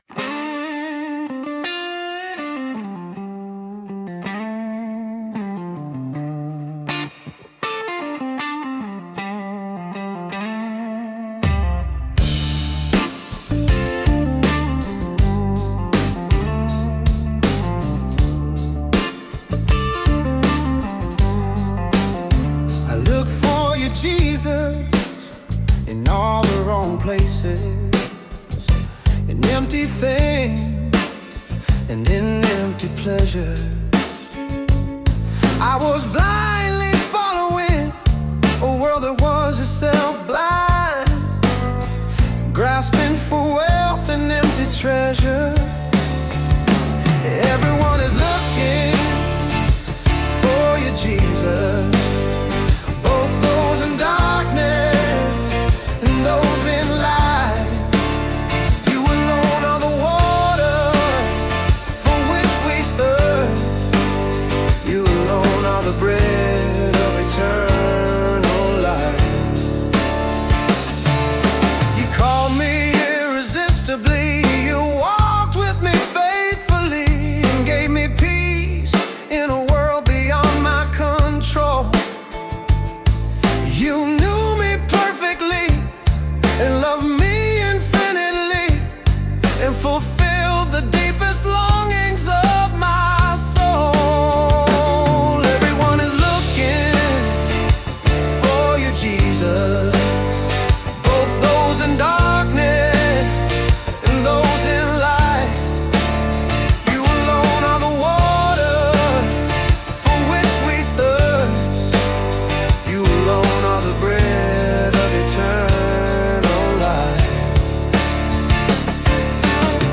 Rock/Soul